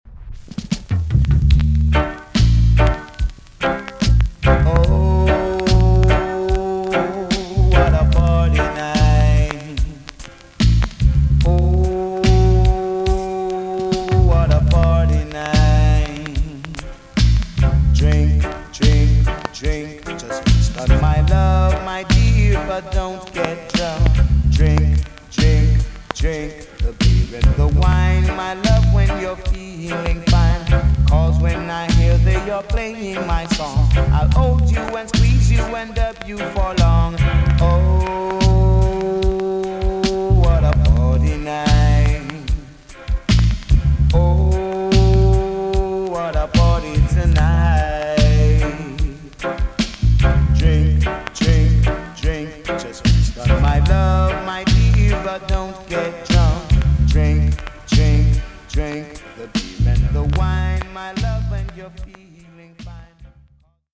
TOP >80'S 90'S DANCEHALL
VG+ 軽いプチノイズがあります。
1983 , NICE VOCAL TUNE!!